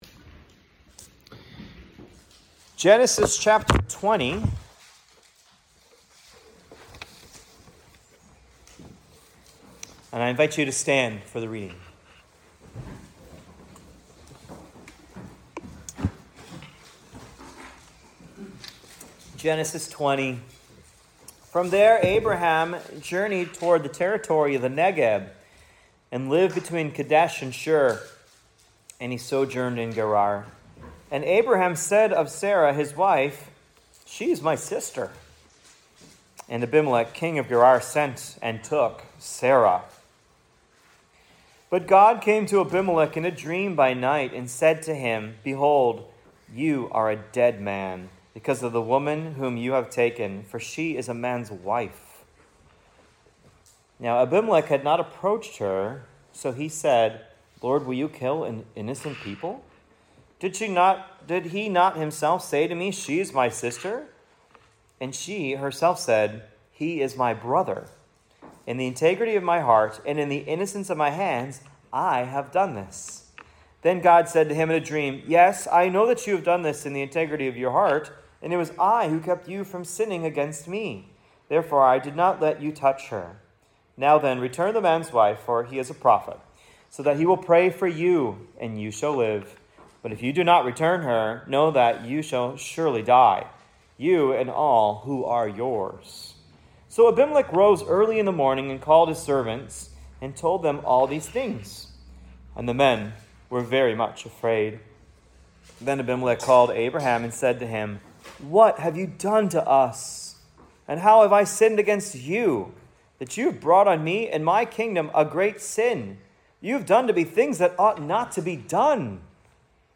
Genesis 20 Sermon Outline: The Lies of Abraham and Faithfulness of God